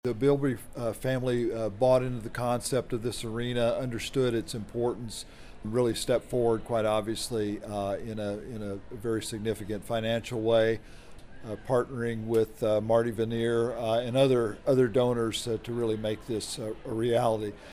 Friday’s ceremony was held inside the Stanley Stout Center, on the northern edge of the campus.